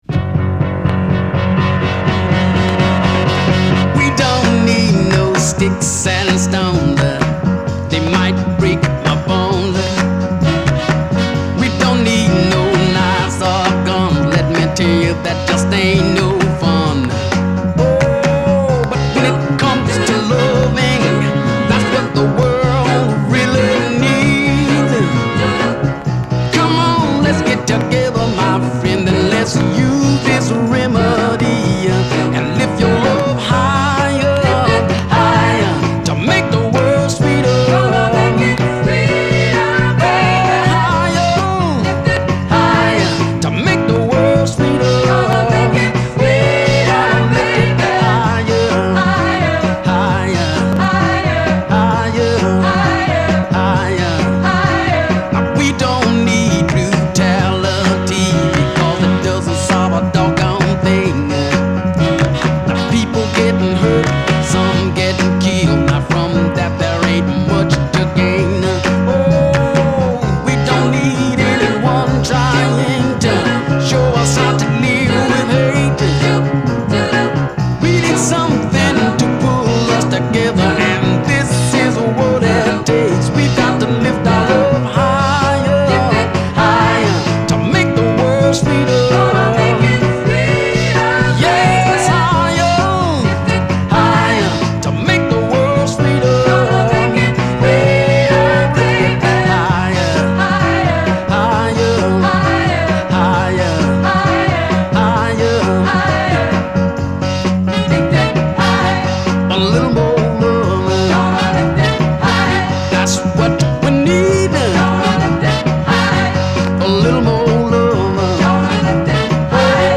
Posted by on December 2, 2014 in Soul/R&B and tagged , , .